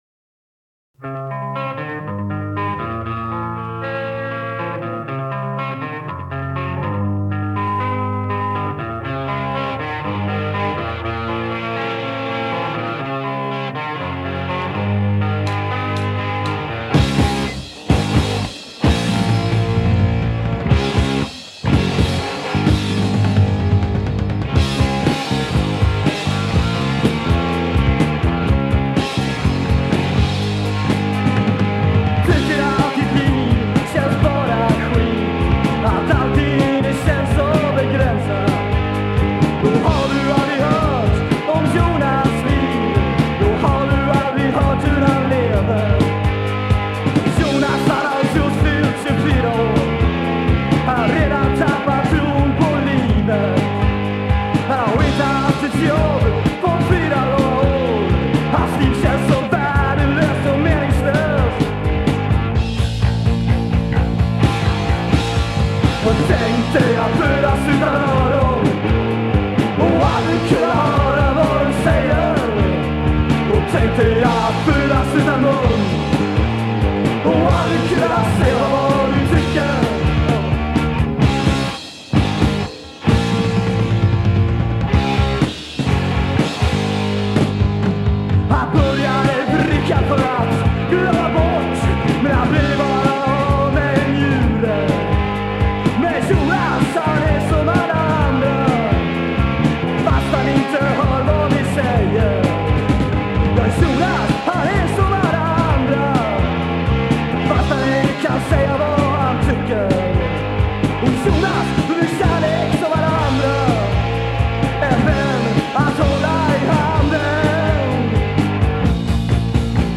Trummor
Gitarr
Sång